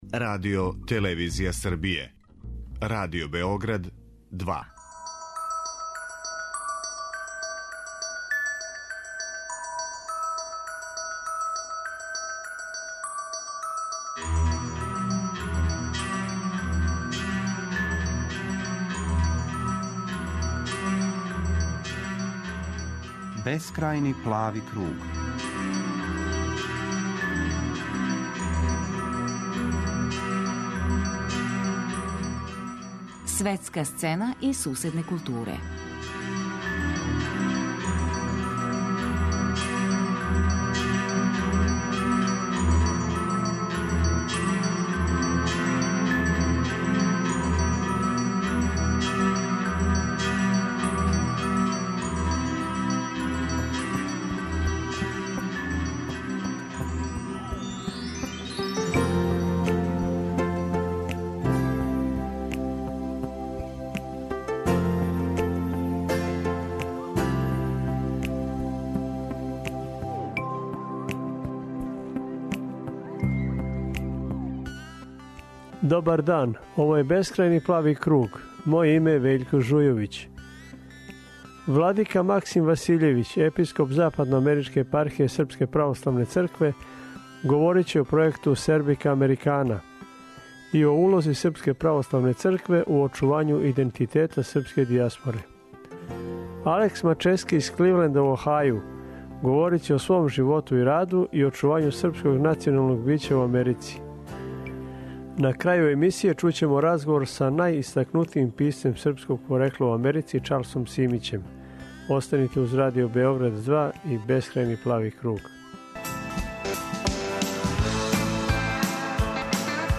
На крају емисије чућемо разговор са најистакнутијим писцем српског порекла у Америци - Чарлсом Симићем.